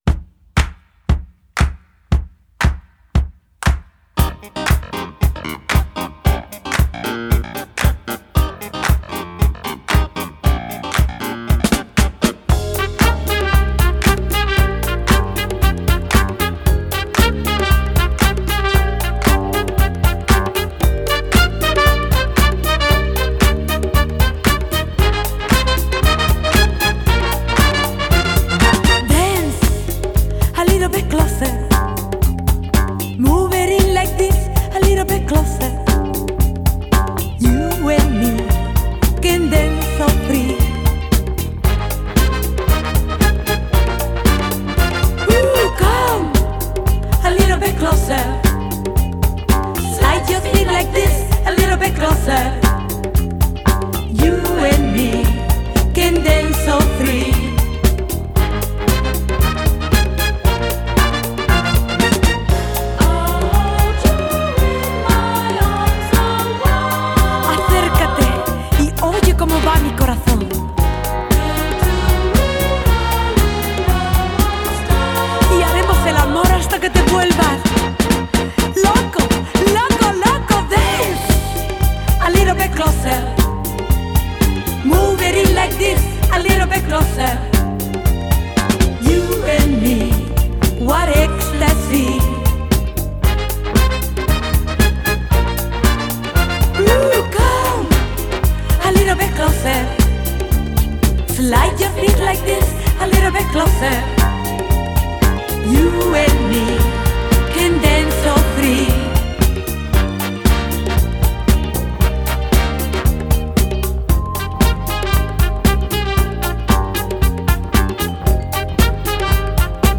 Genre: Funk / Soul, Disco